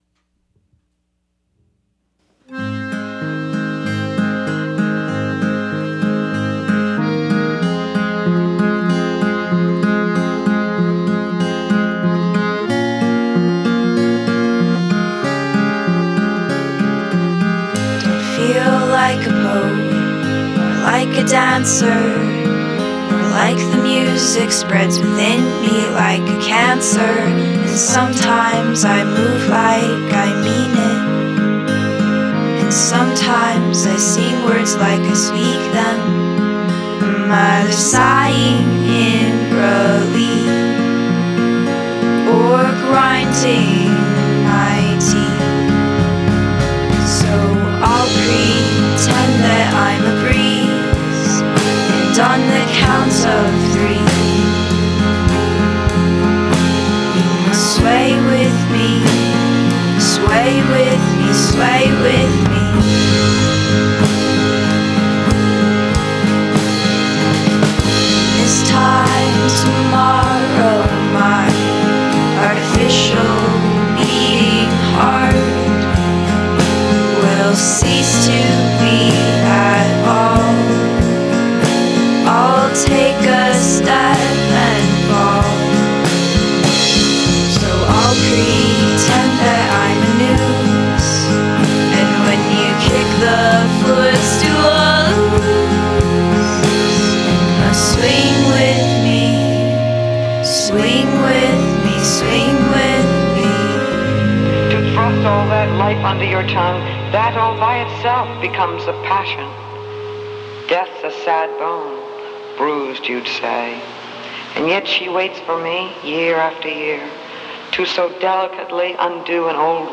Organique. Intime. Aérien.